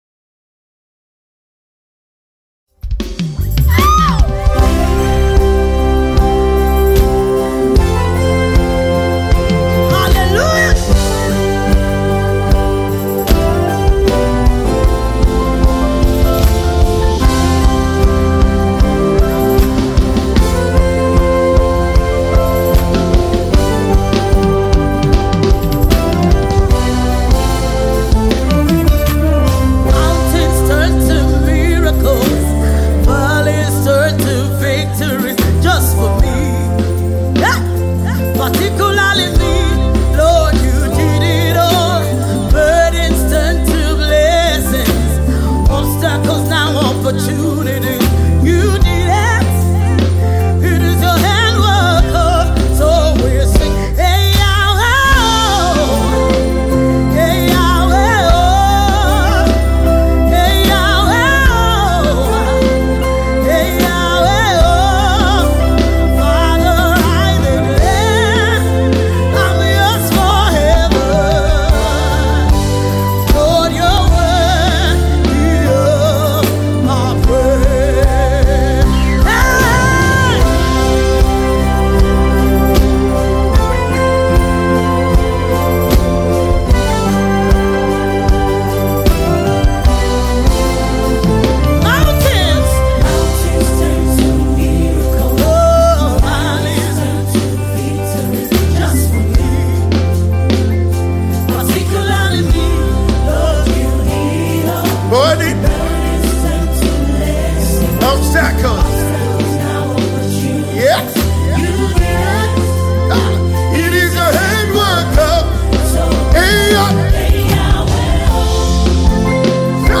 Acclaimed Nigerian leading music duo